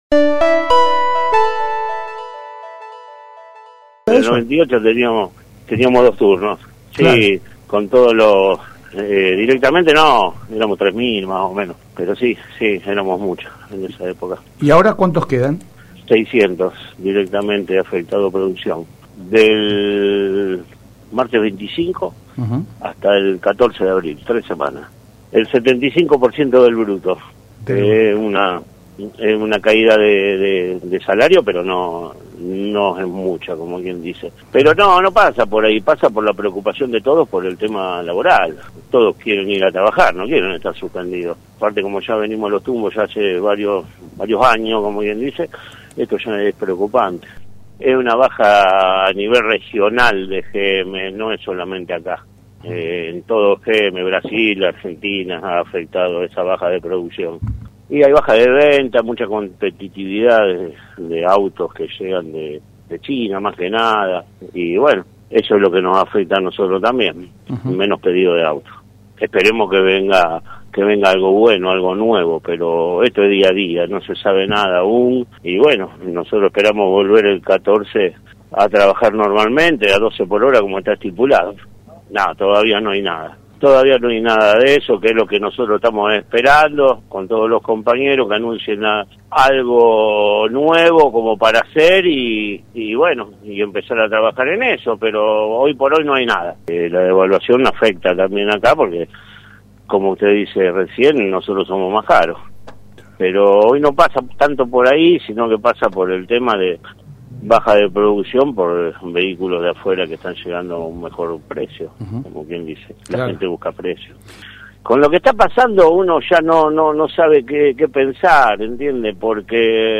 confirmó en diálogo con LT3 que la suspensión afectará a los 600 trabajadores de la planta